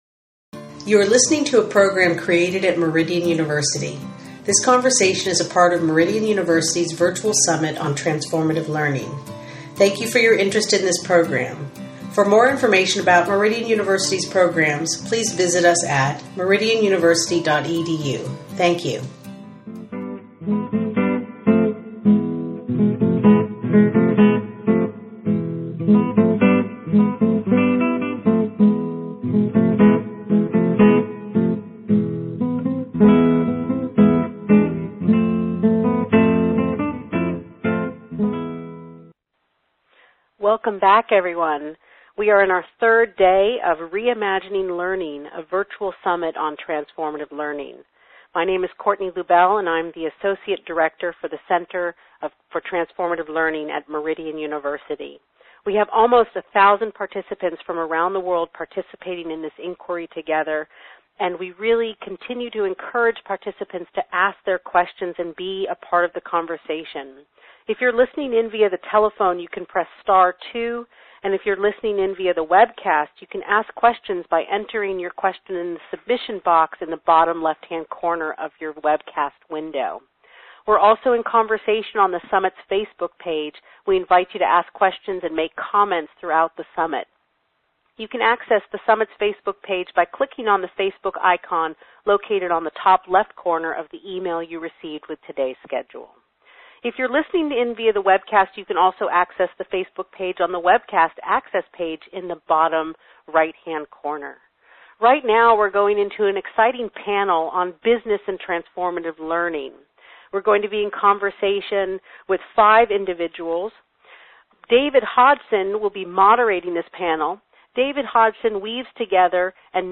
Last weekend, I had the pleasure to be on a panel on the topic of Transformative Learning in Business.